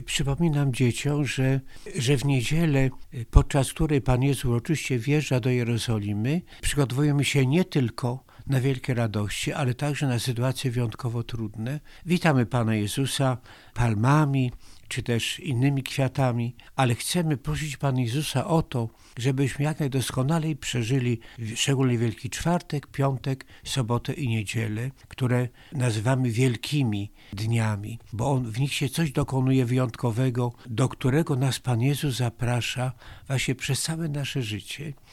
Mówi bp Antoni Długosz: